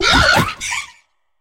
Cri de Flamenroule dans Pokémon HOME.